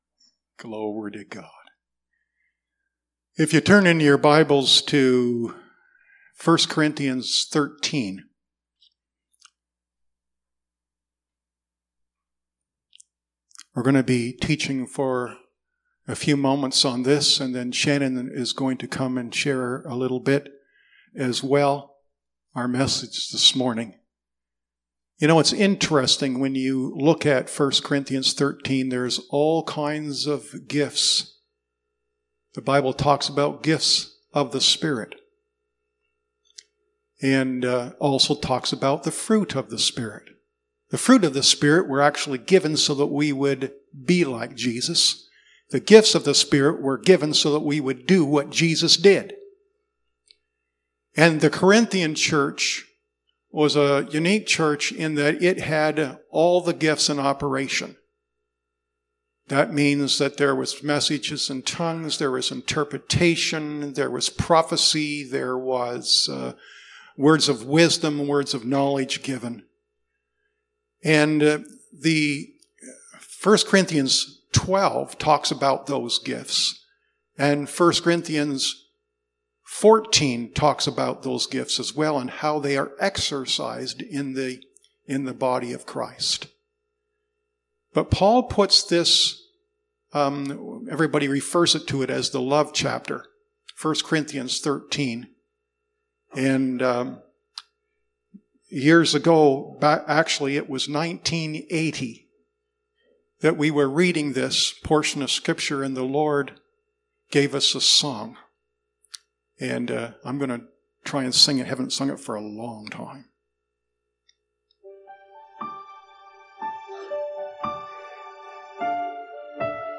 Sermons | Bethel Assembly